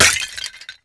ceramic_impact6.wav